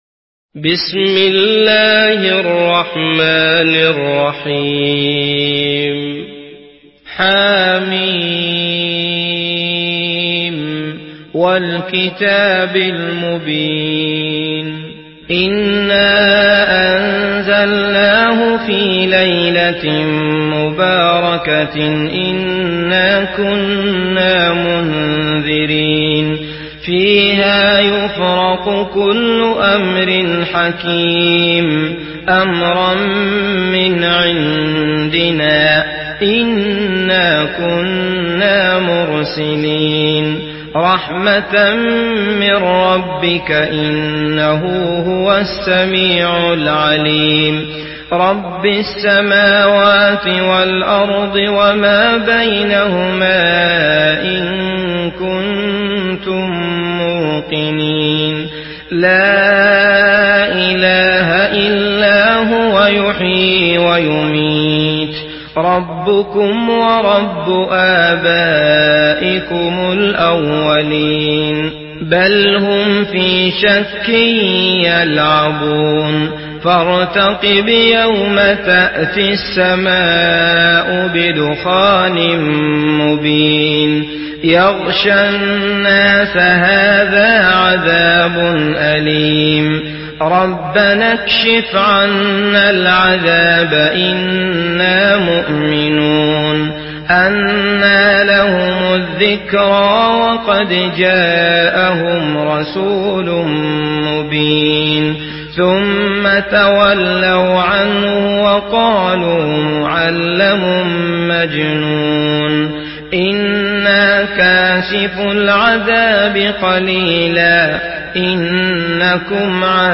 Surah الدخان MP3 by عبد الله المطرود in حفص عن عاصم narration.
مرتل